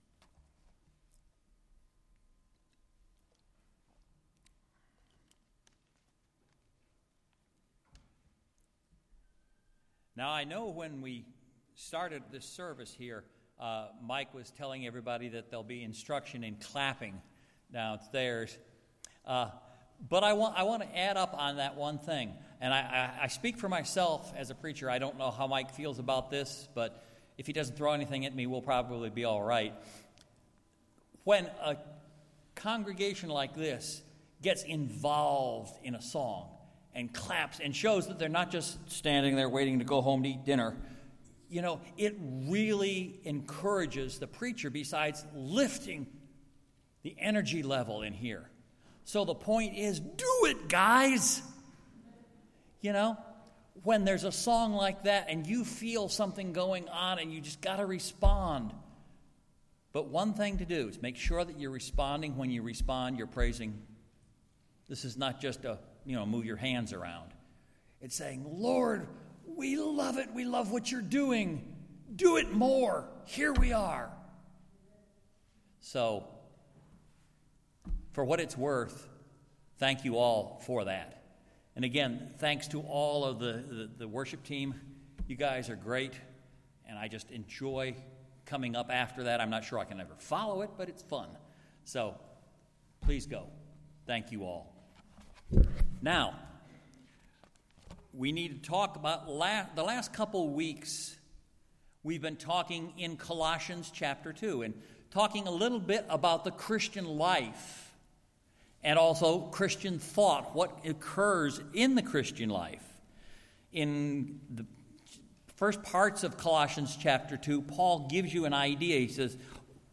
Sermons | Calvary Baptist Bel Air